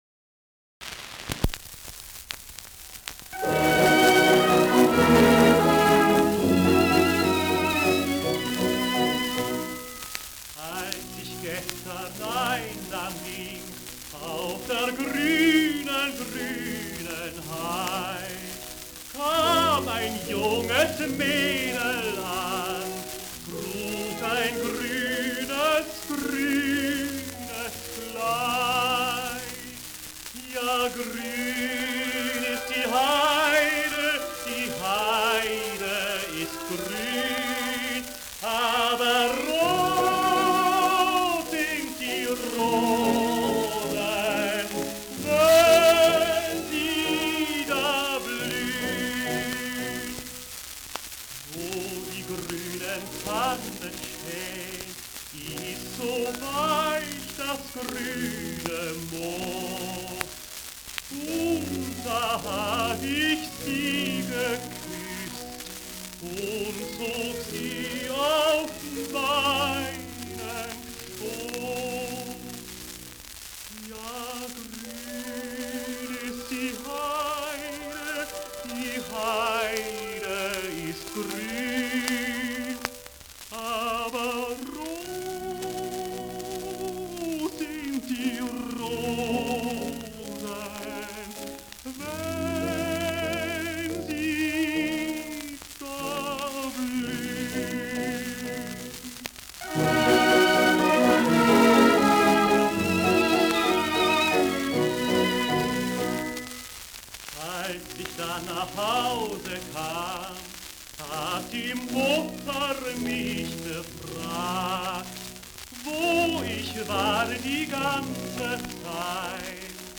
Schellackplatte
leichtes Rauschen